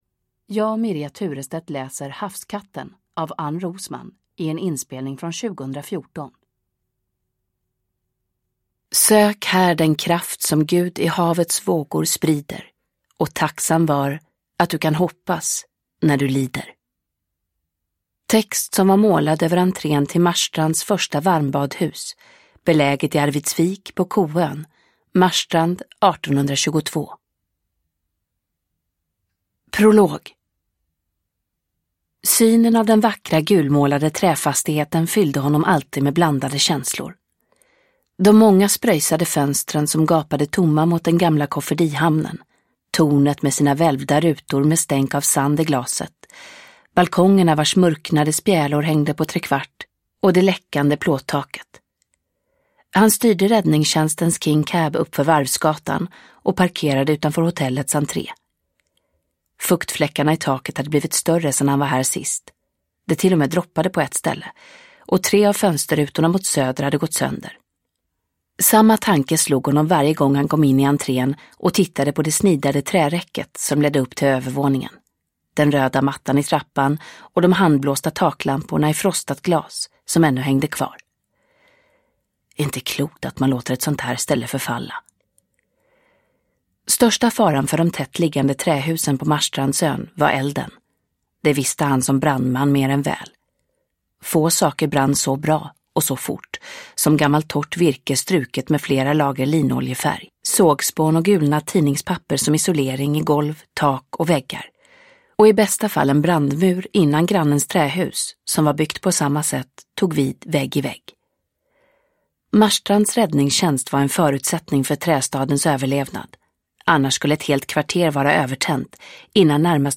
Uppläsare: Mirja Turestedt
Ljudbok